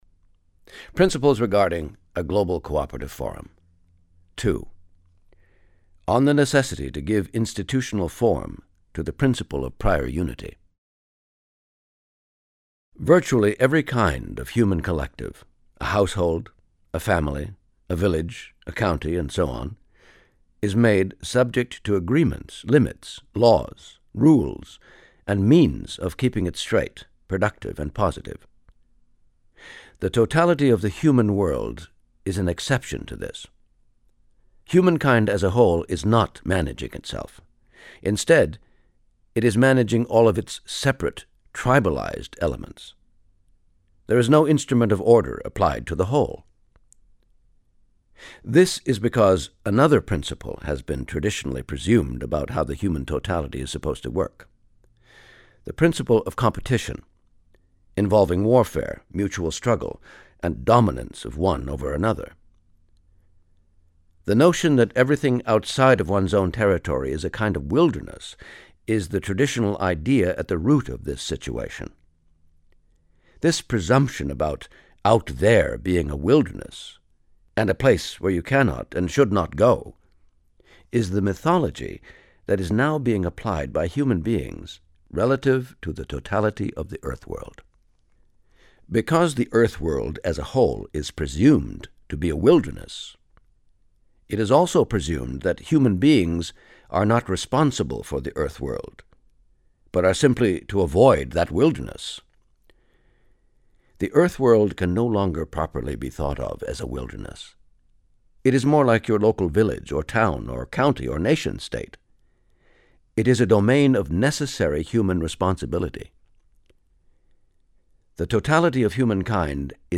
Renowned actor Kenneth Welsh recites selected chapters from Adi Da's book, Not-Two Is Peace.